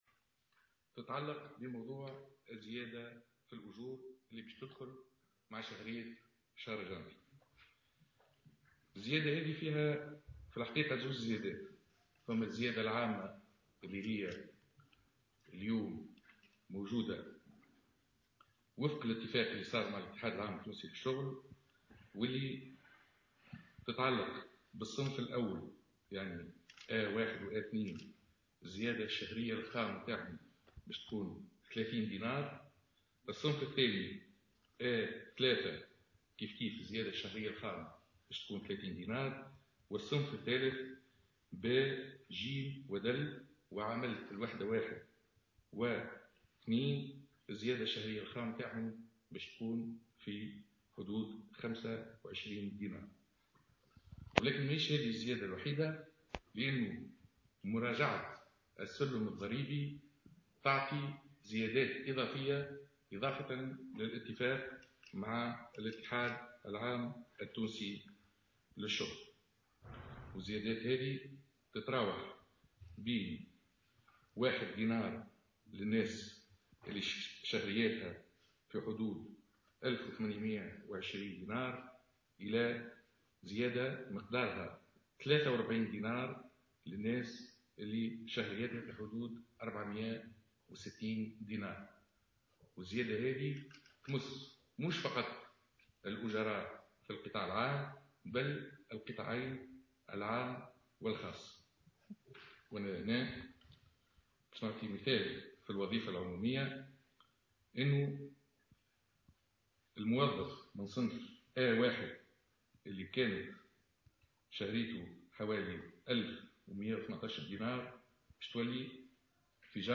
Le porte-parole officiel du gouvernement, Iyed Dahmani a dévoilé, jeudi, le montant des majorations salariales décidées au profit des travailleurs du secteur public qui entreront en vigueur à partir de Janvier 2017.